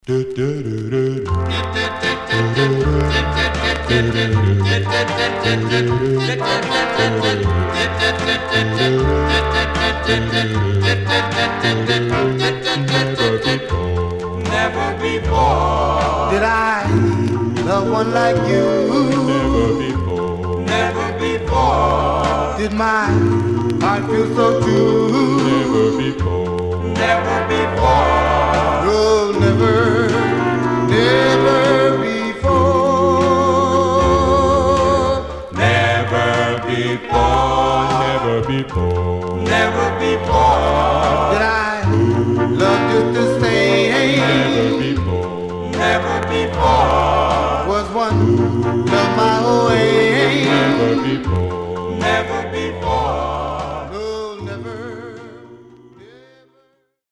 Mono
Male Black Group Condition